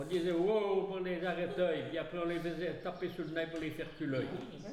Il fait arrêter ou reculer les bœufs
Locution